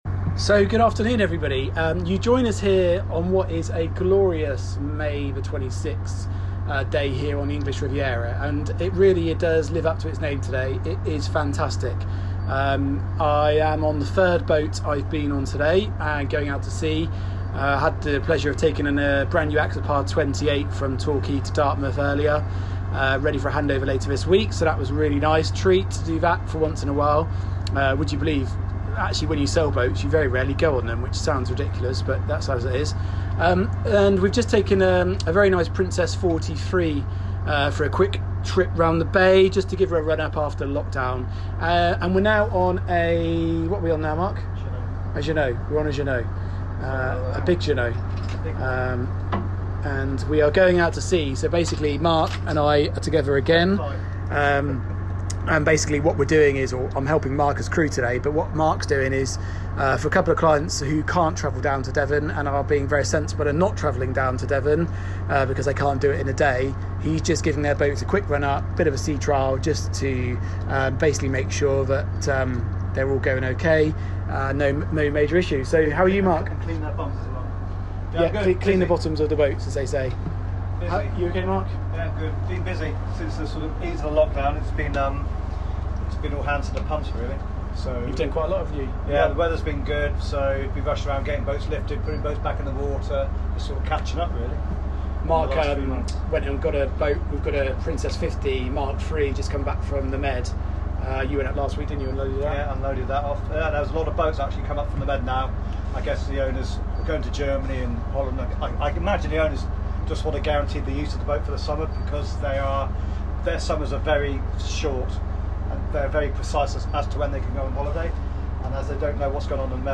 Slight hiccup with the recording at the end of the chat but have a listen!